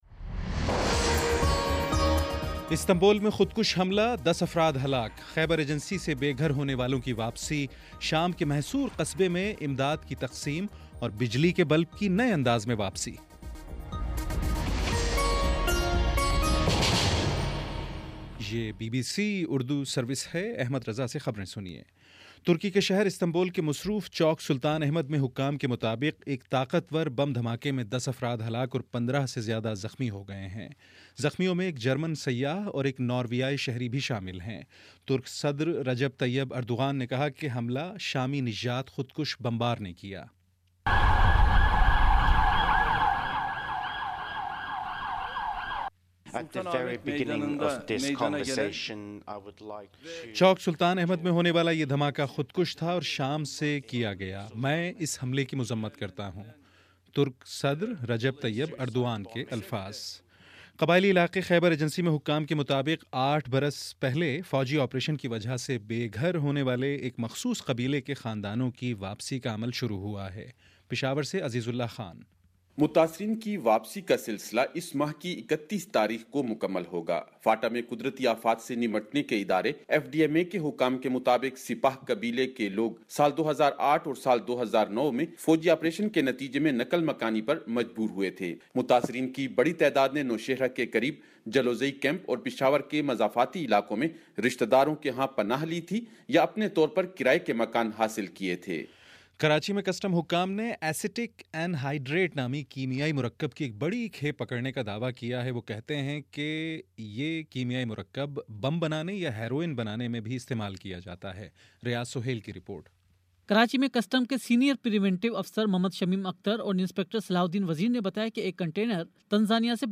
جنوری 12 : شام چھ بجے کا نیوز بُلیٹن